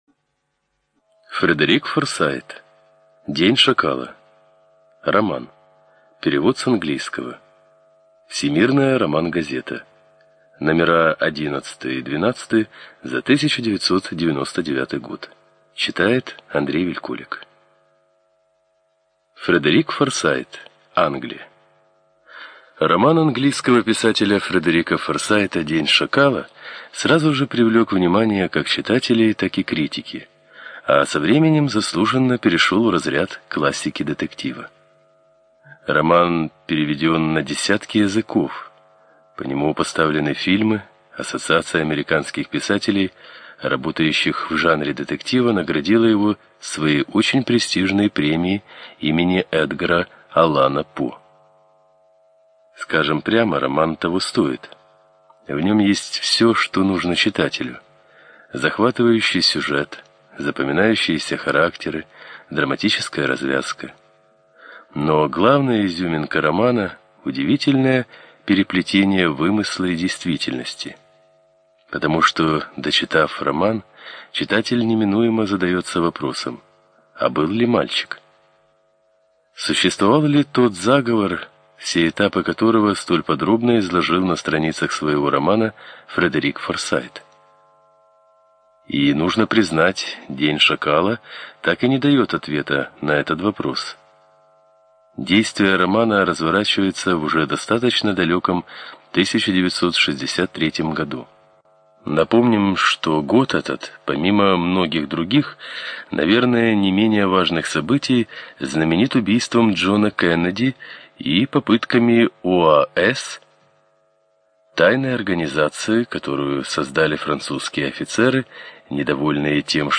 ЖанрДетективы и триллеры